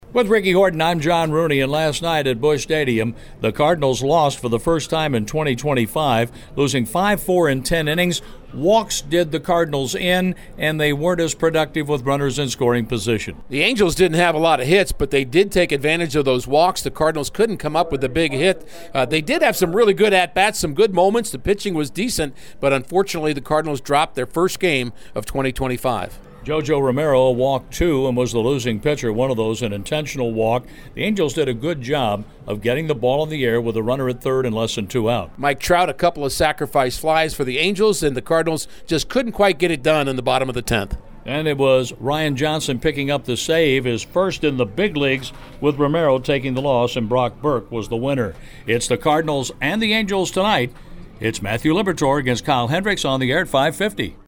Gamerecap40.mp3